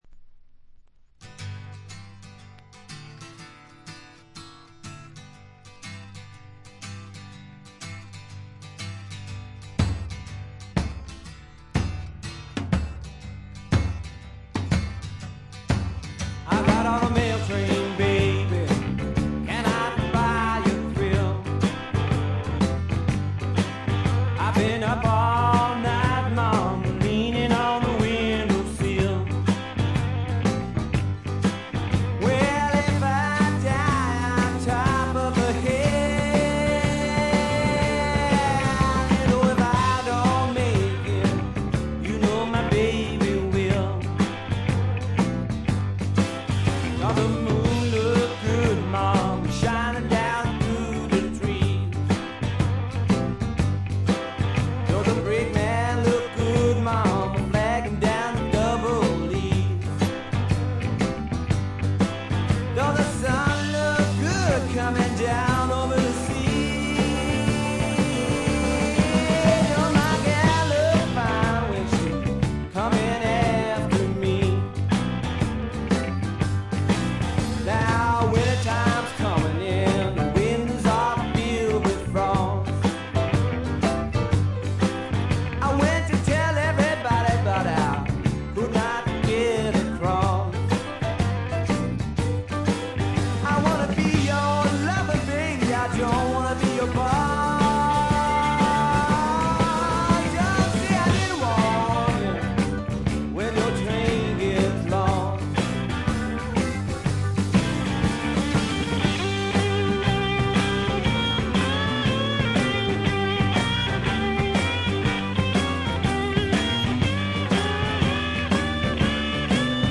これ以外は軽いチリプチ少々、散発的なプツ音2-3回という程度で良好に鑑賞できると思います。
試聴曲は現品からの取り込み音源です。
Recorded at Larrabee Sound , Holywood , California